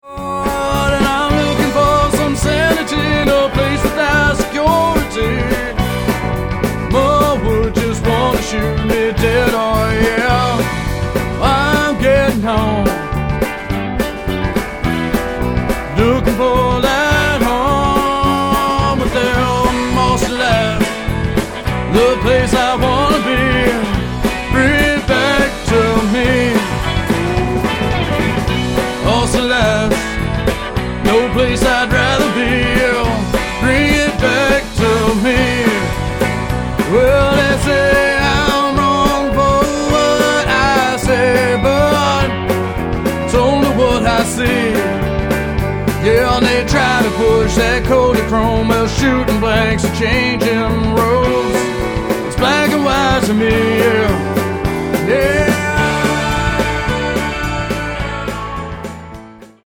The tracks were recorded primarily "live"
stripped down and hard-rocking.